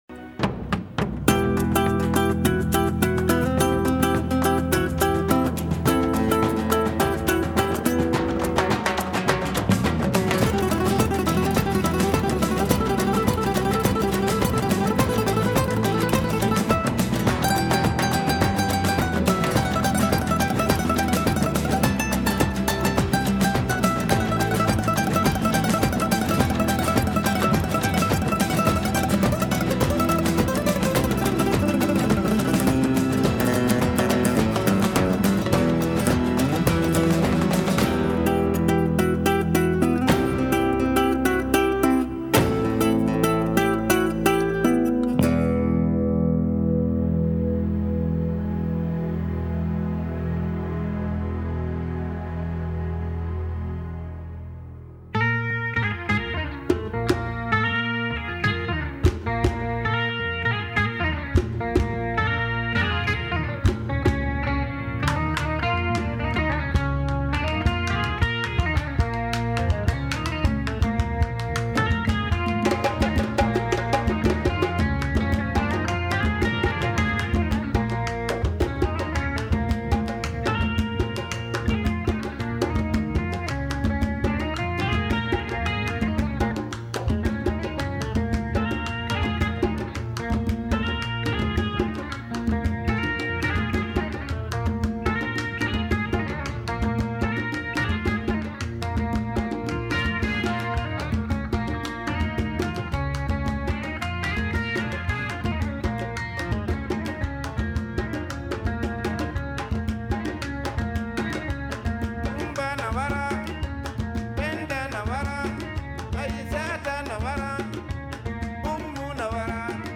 Flamenco, Moorish, African, South American, Jazz influences